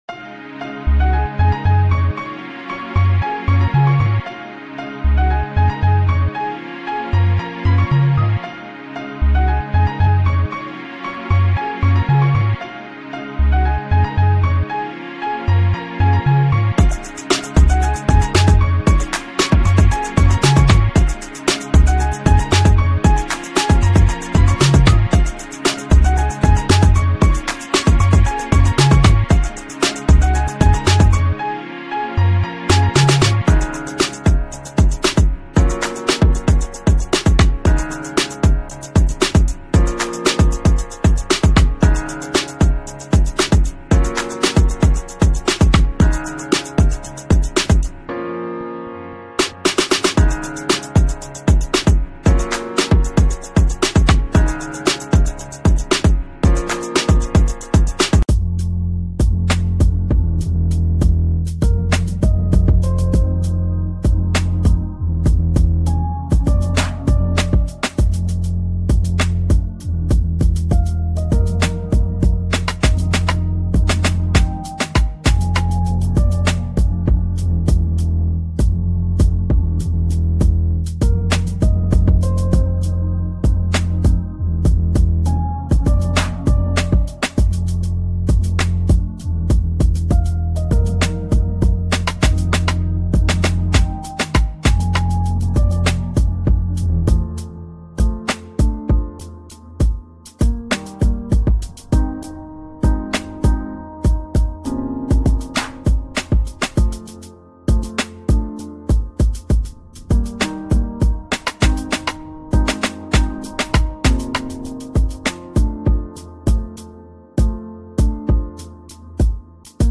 Nature Relaxation Video